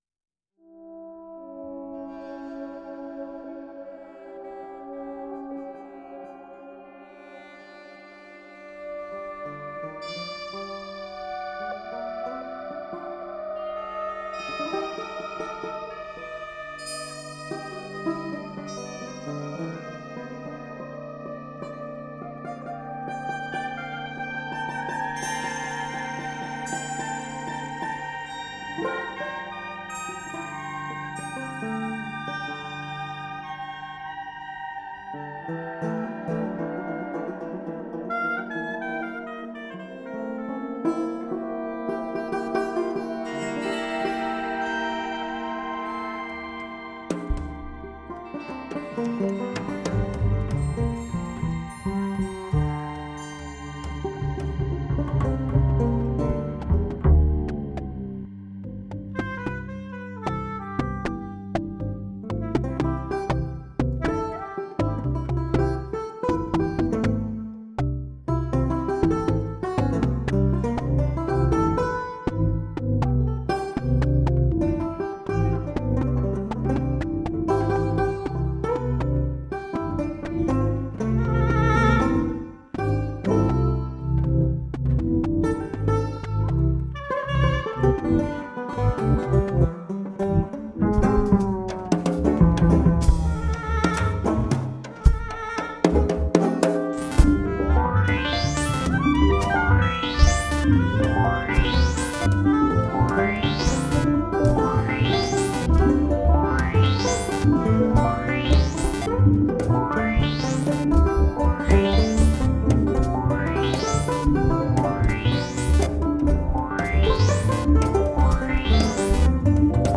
horns